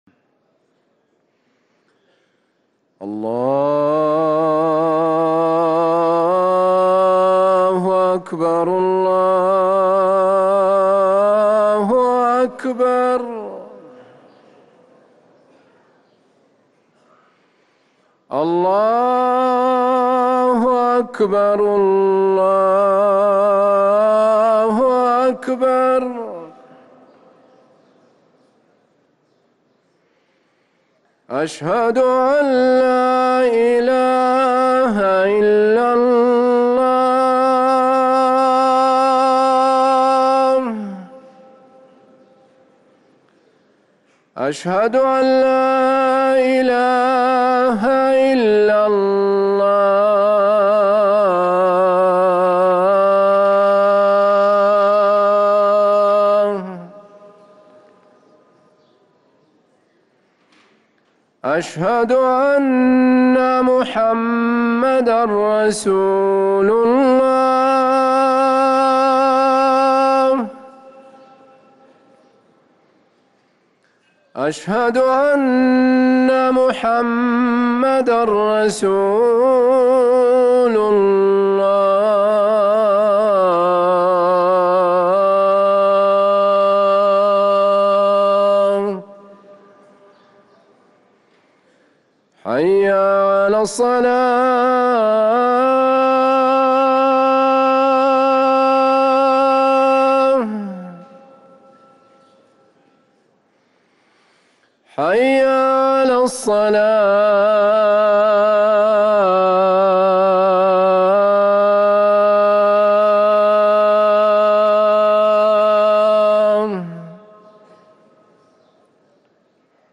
ركن الأذان 🕌